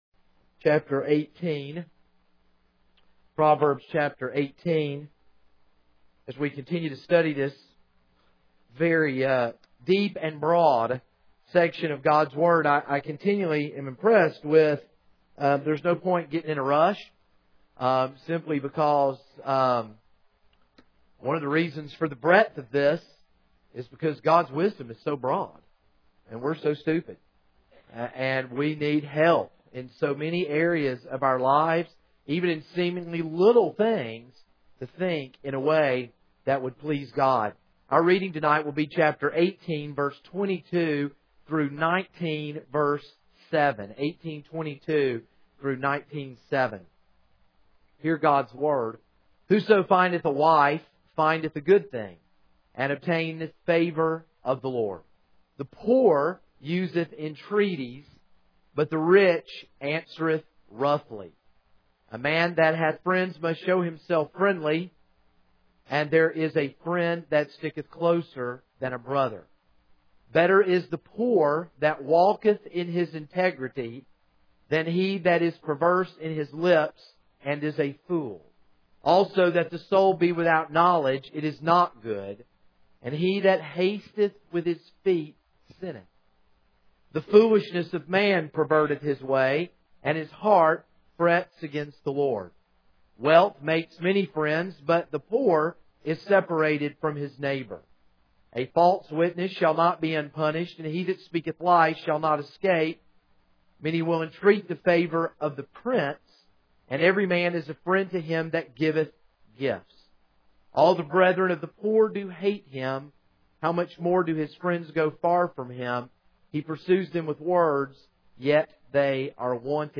This is a sermon on Proverbs 18:22-19:7.